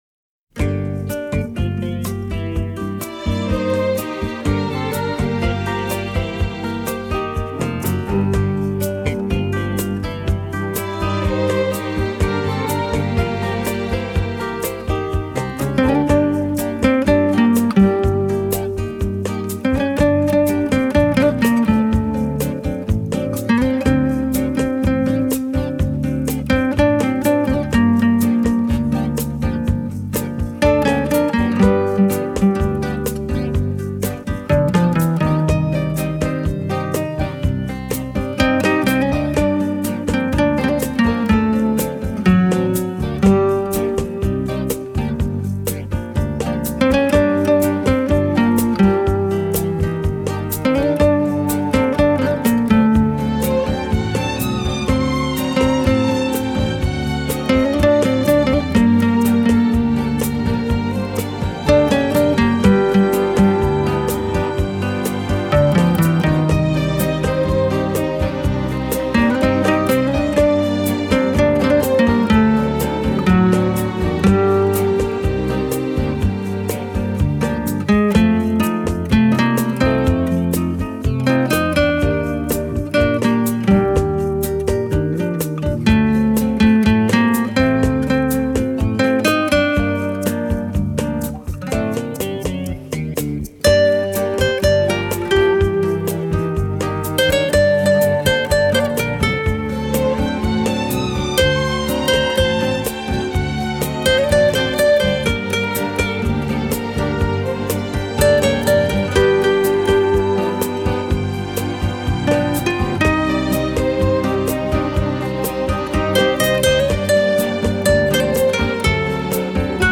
обработка финского вальса